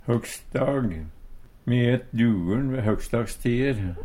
høgsdag midt på dagen Eintal ubunde Eintal bunde Fleirtal ubunde Fleirtal bunde Eksempel på bruk Me et dugguL ve høgsdagstier. Tilleggsopplysningar Eit utelleleg substantiv Høyr på uttala Ordklasse: Substantiv hankjønn Kategori: Tida (dagen, året, merkedagar) Attende til søk